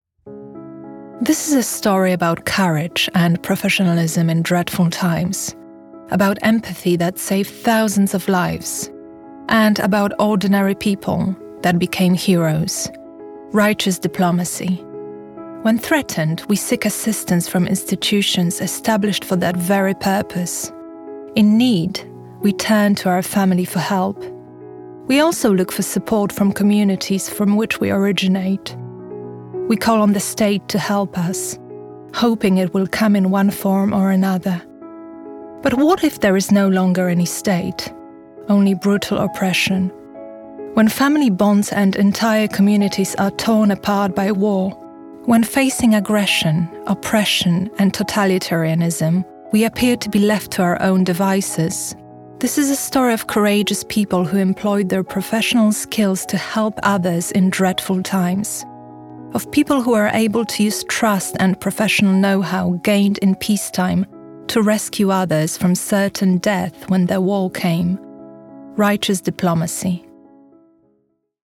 Narration
RODE NT1A, cabine vocale entièrement isolée dans un placard. Interface Scarlett 2i2, MacBook Air, Adobe Audition
Mezzo-soprano